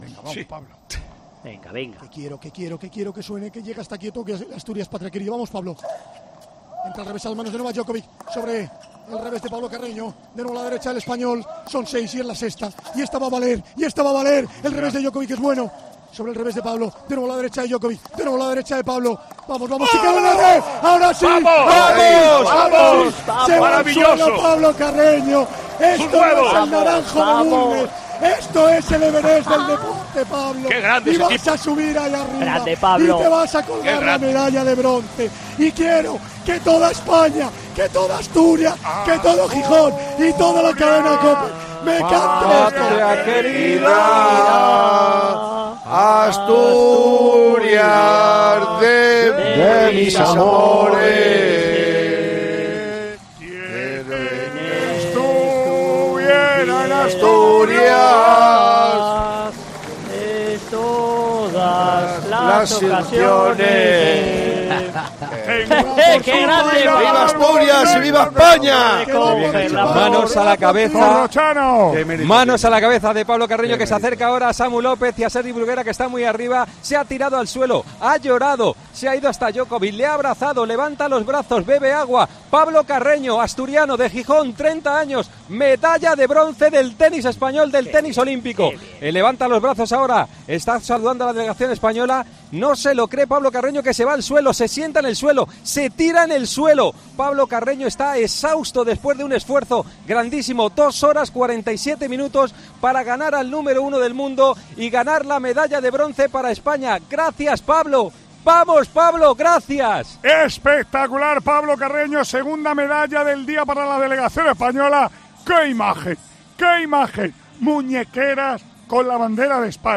A continuación puedes escuchar cómo hemos narrado en 'Tiempo de Juego', con nuestro equipo de enviados especiales, las 17 medallas que ha conseguido nuestro país en Tokio.